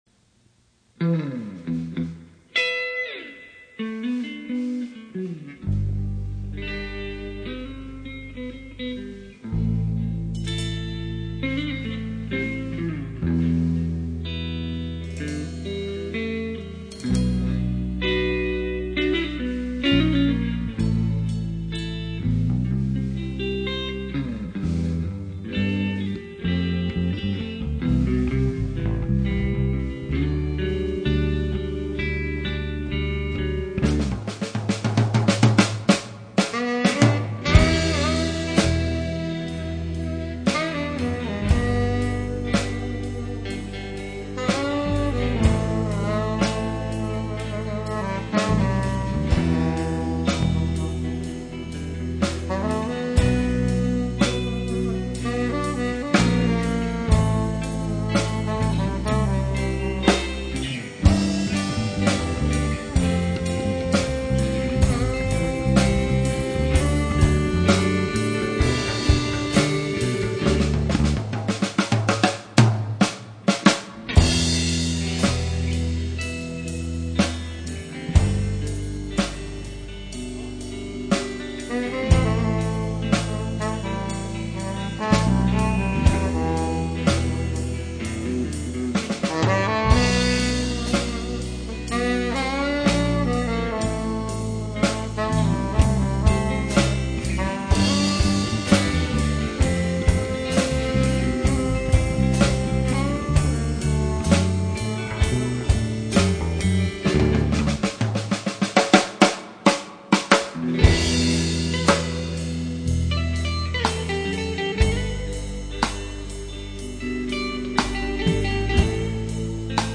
Sax
Guitar
Drums